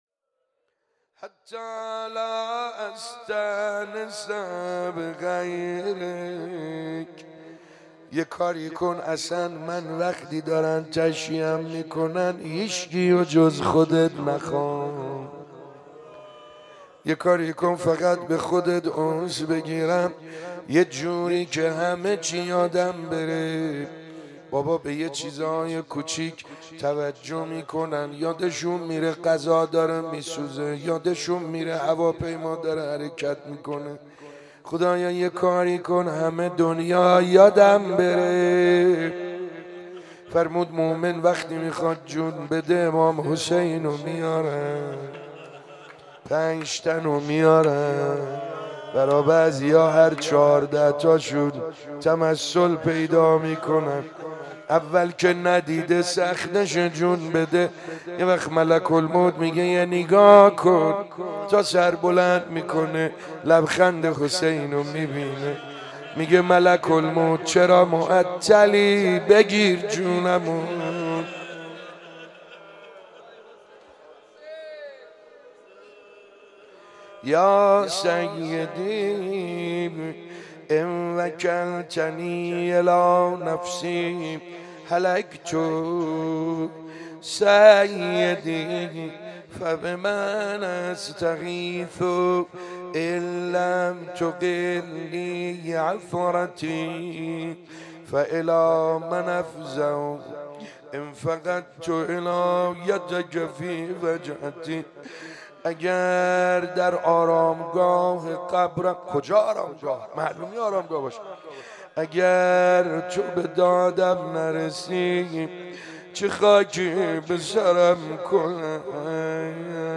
شب 22 ماه مبارک رمضان 95_دعا خوانی